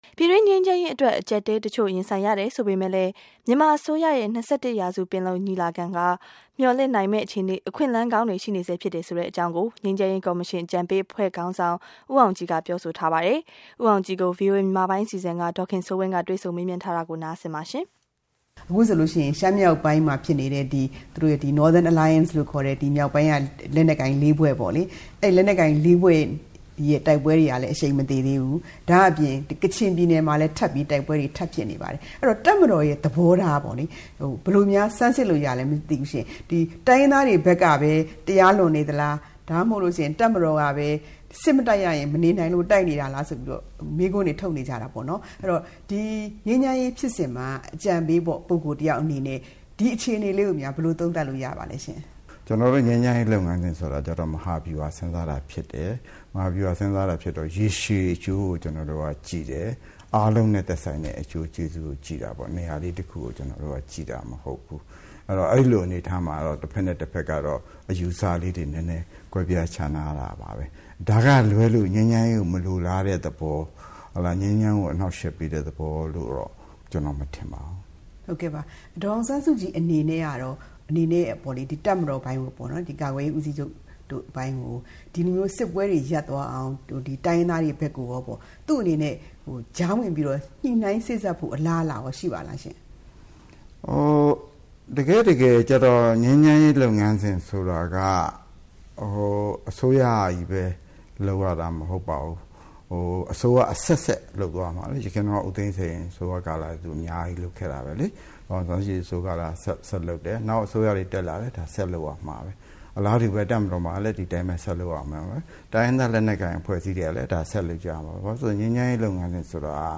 ငြိမ်းချမ်းရေးကော်မရှင် အကြံပေးခေါင်းဆောင် ဦးအောင်ကြည်နဲ့တွေ့ဆုံမေးမြန်းခြင်း